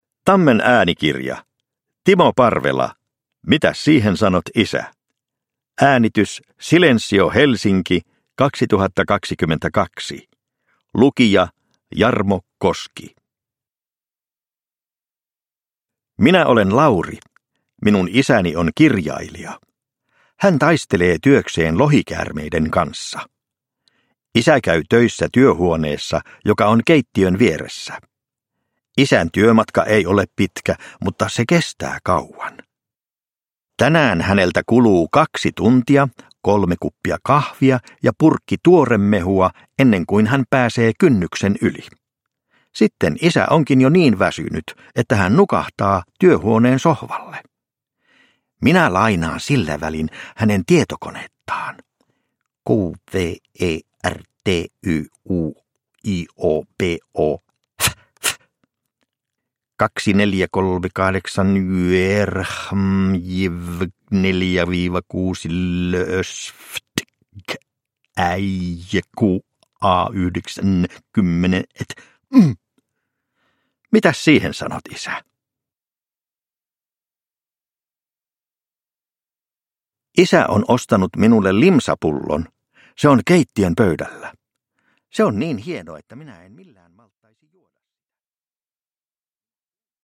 Mitäs siihen sanot, isä? – Ljudbok – Laddas ner